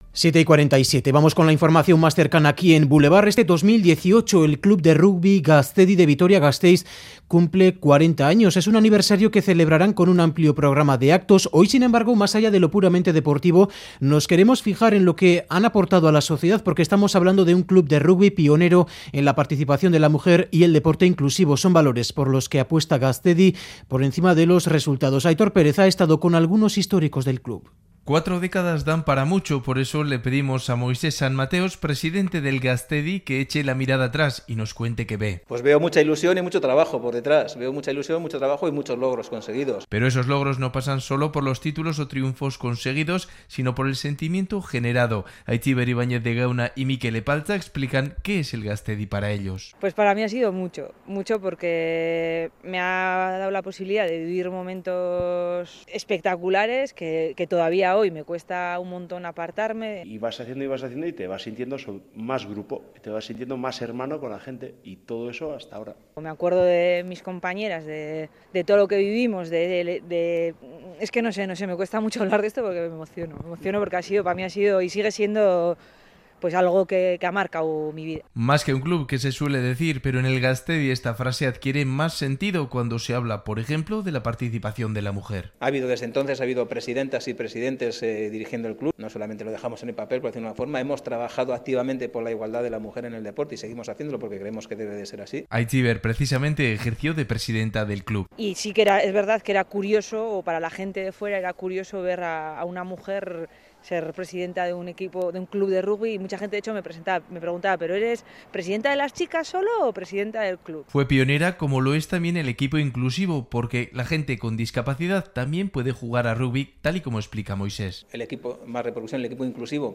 Radio Euskadi REPORTAJES El Club de Rugby Gaztedi de Vitoria, cumple 40 años en 2018 Última actualización: 28/03/2018 09:39 (UTC+2) Para celebrar el aniversario, han organizado varios actos durante todo el año.